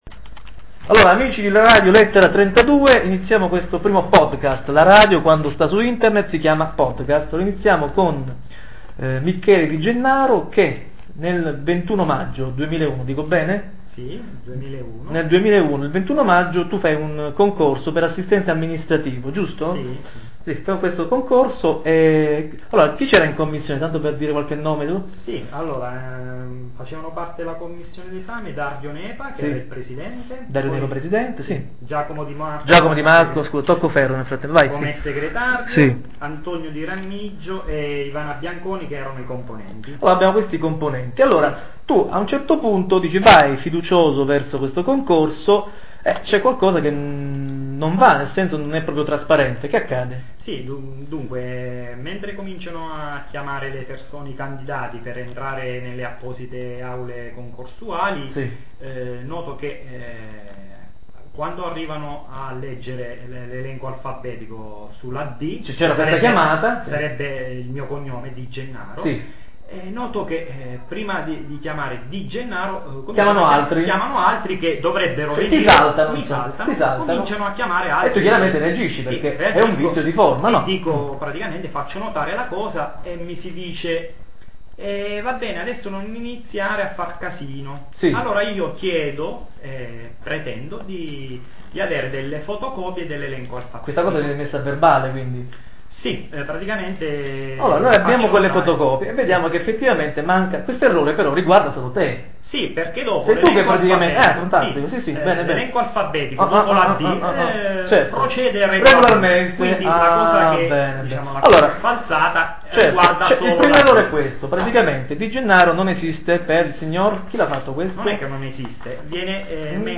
L' audio non è il massimo, ed io come speaker sono troppo invadente ma ci miglioreremo.